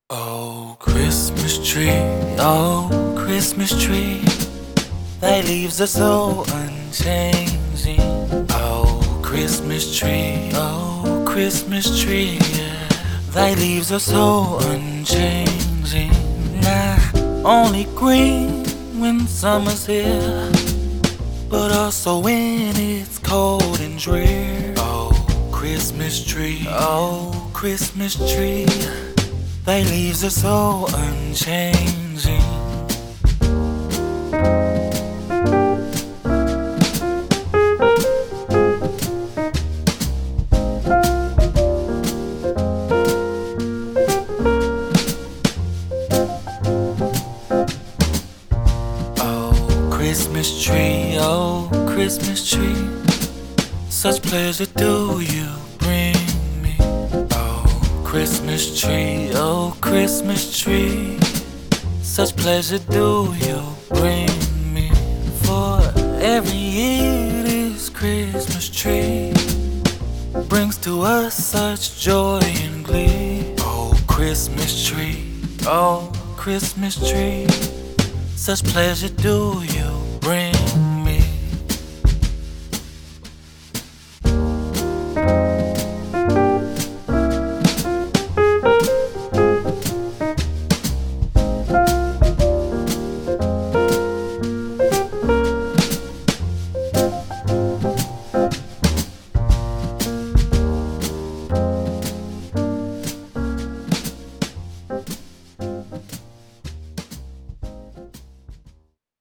Jazz, Holiday
C Min